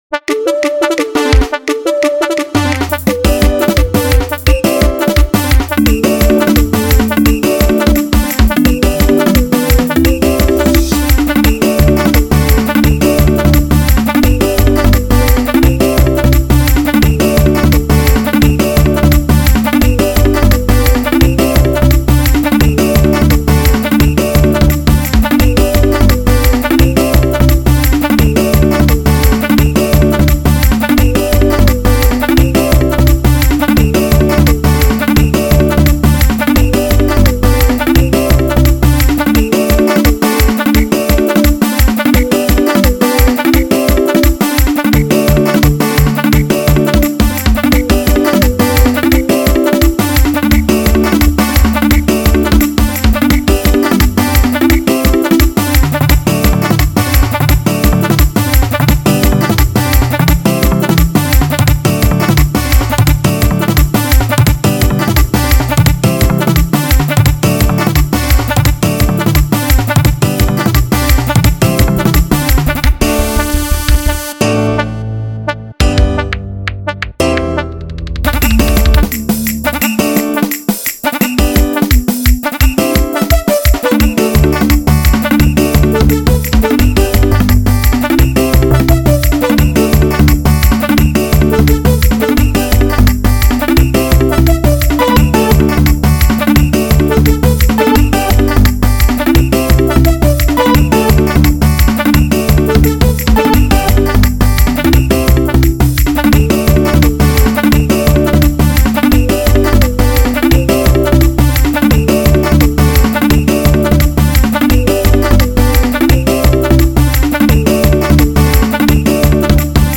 04:24 Genre : Xitsonga Size